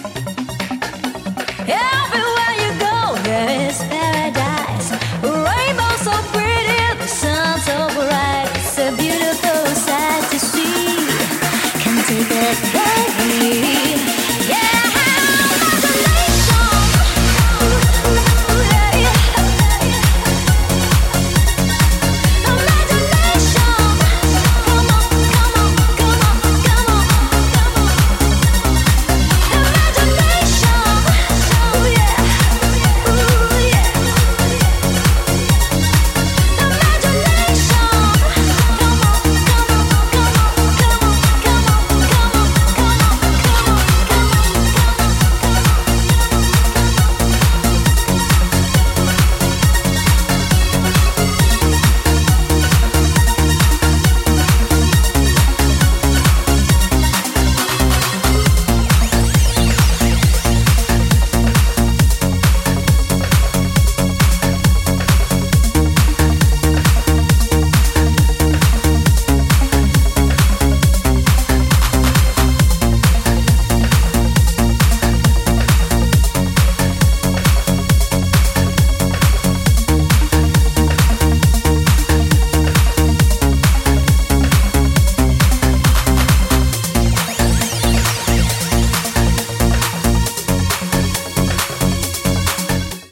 Electronic
TranceHard HouseHard TranceTechnoProgressive TranceJumpstyle